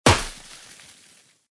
cannon.mp3